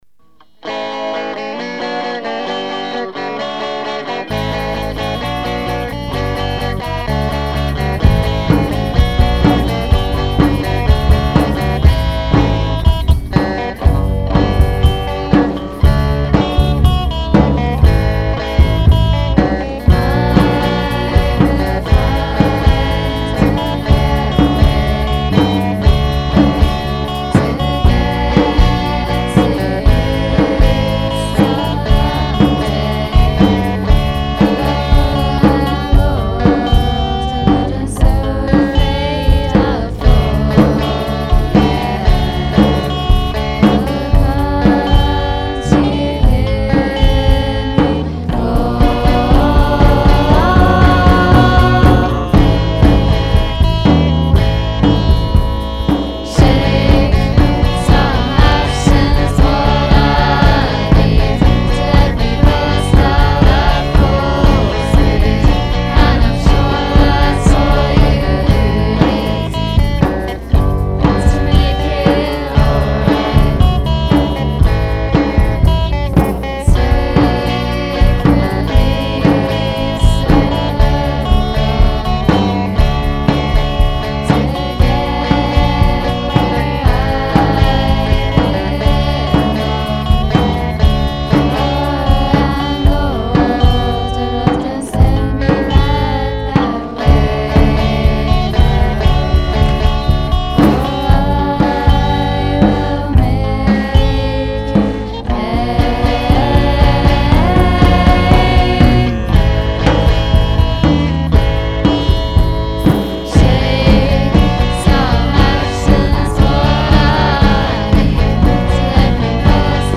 a perfectly minimal set of drums and a violin respectively